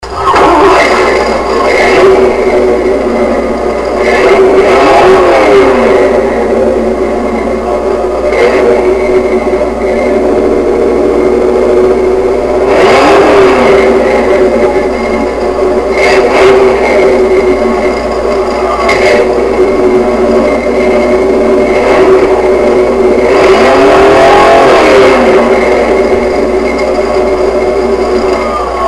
Mustang Exhaust Clips
Mustang Exhaust Sound/Movie Clips Page: